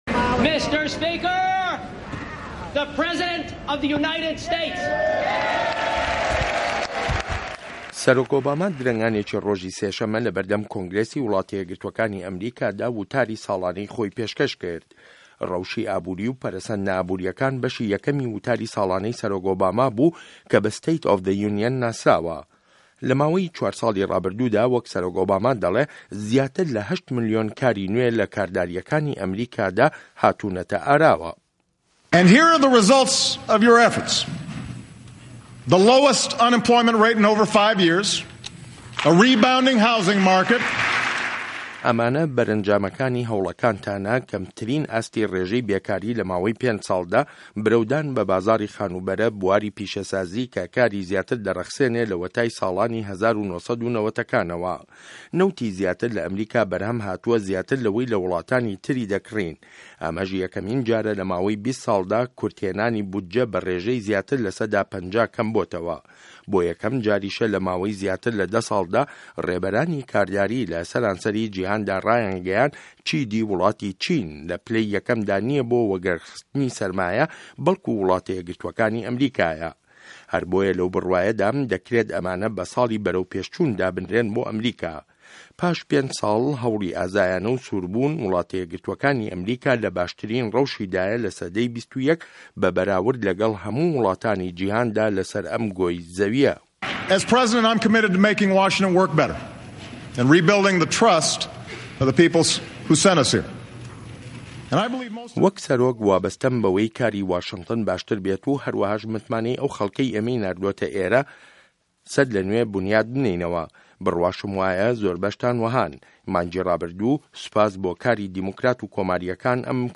چه‌ند به‌شێک له‌ وتاره‌که‌ی سه‌رۆک ئۆباما